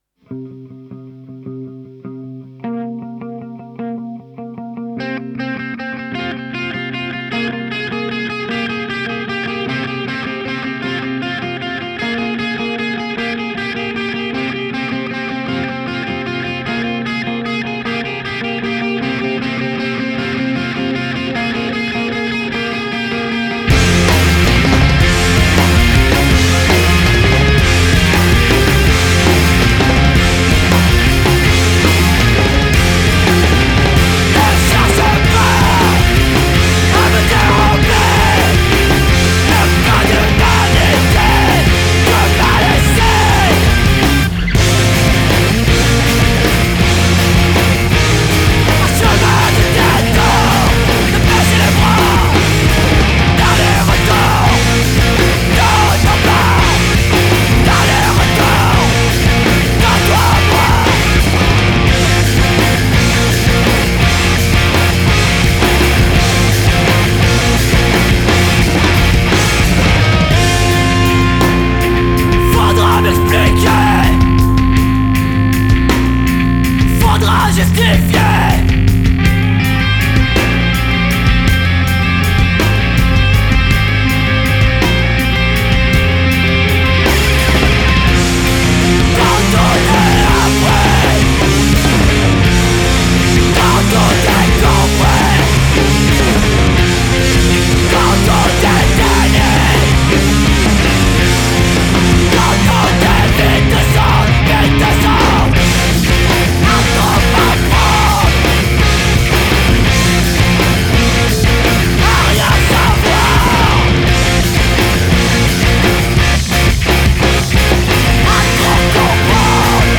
screamo post hardcore